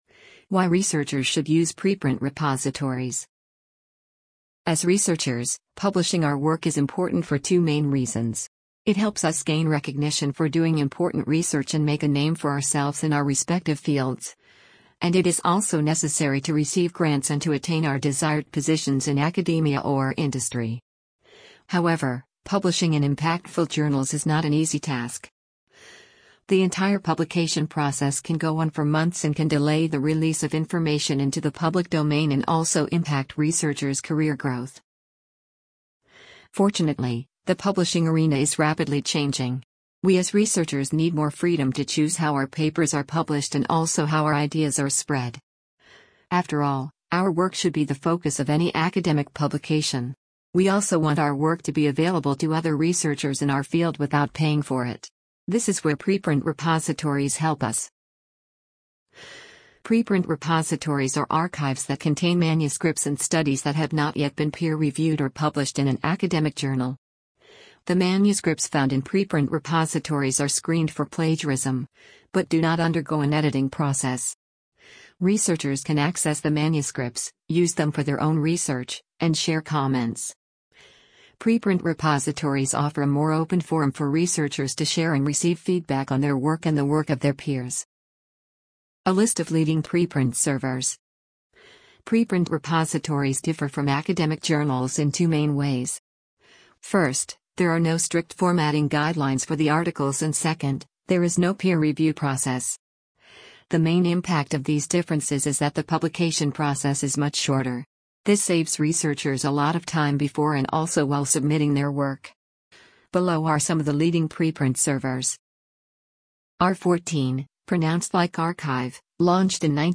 amazon_polly_160.mp3